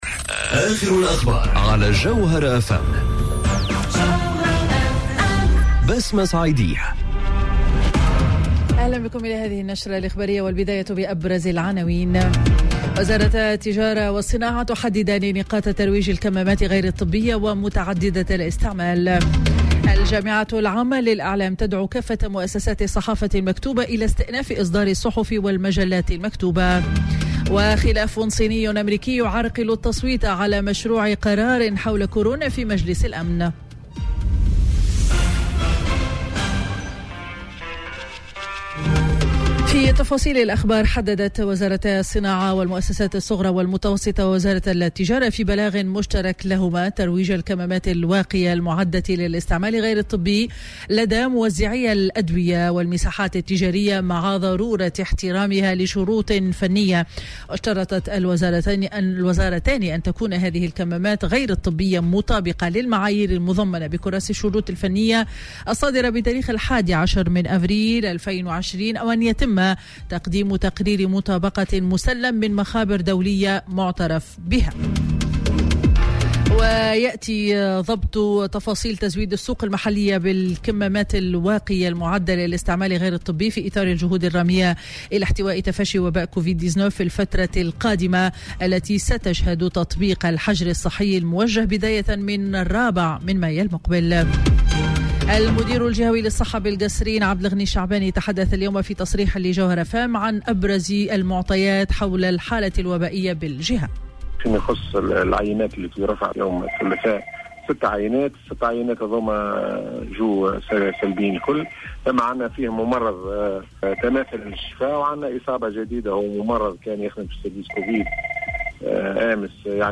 نشرة أخبار منتصف النهار ليوم الخميس 30 أفريل 2020